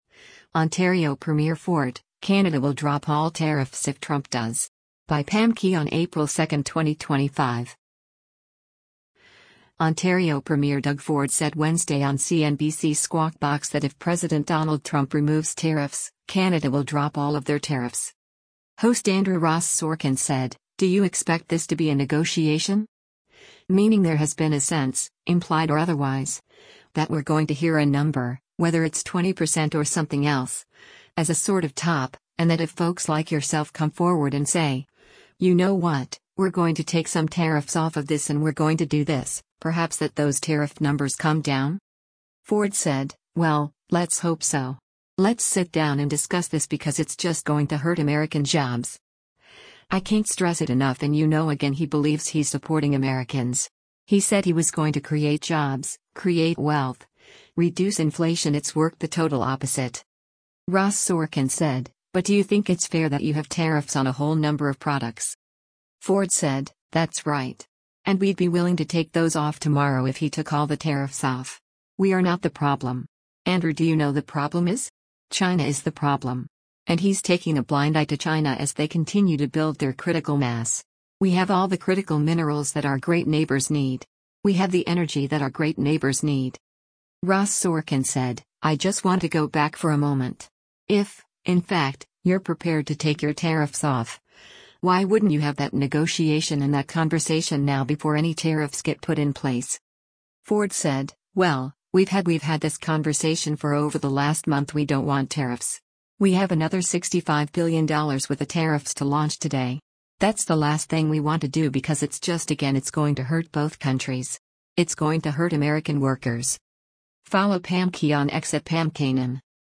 Ontario Premier Doug Ford said Wednesday on CNBC’s “Squawk Box” that if President Donald Trump removes tariffs, Canada will drop all of their tariffs.